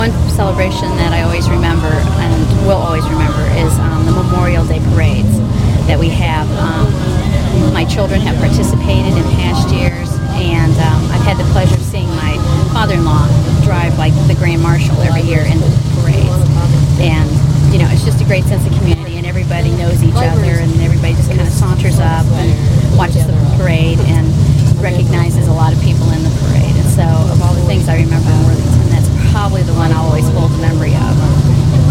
In July 2001 Worthington Libraries invited the community to share their earliest memories and fondest recollections of life in Worthington at the Worthington FolkFEST.
with genre interview.